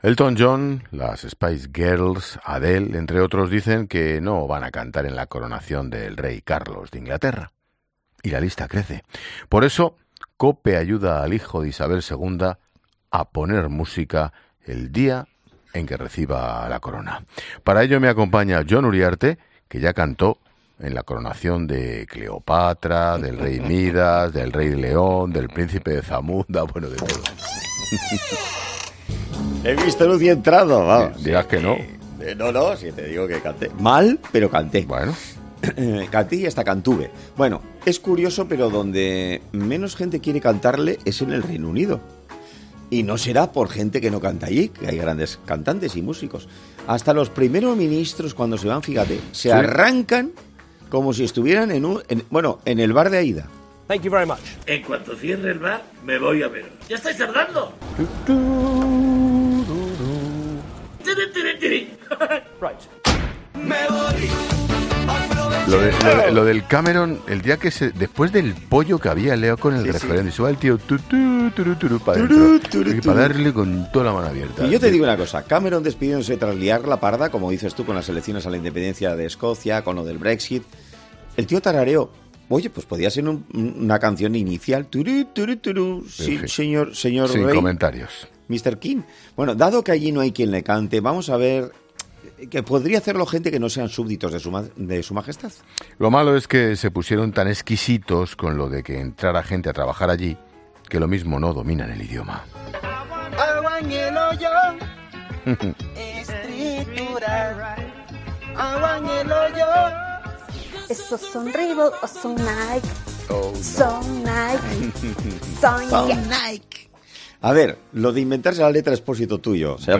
Otro de las propuestas musicales que le ha desatado carcajadas es la de un hombre cantando 'Ese toro enamorado de la luna' mientras bebe vino de una bota.